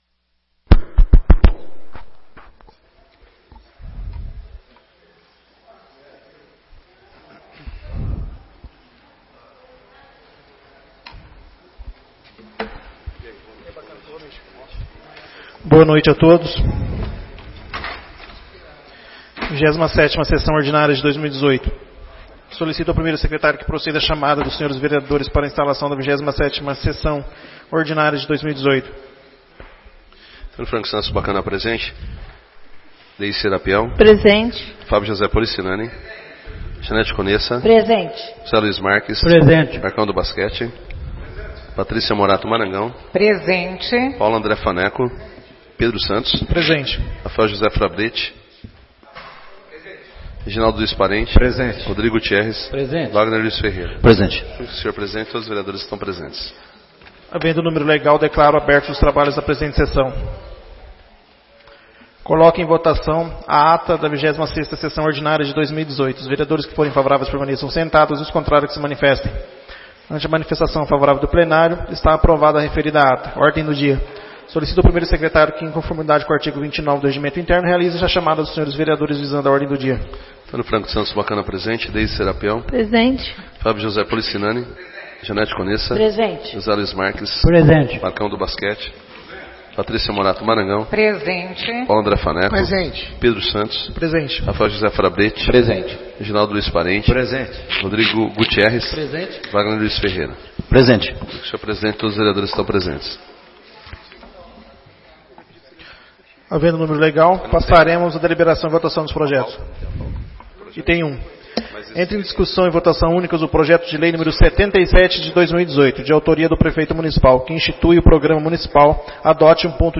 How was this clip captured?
27ª Sessão Ordinária de 2018